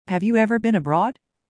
【ノーマル・スピード】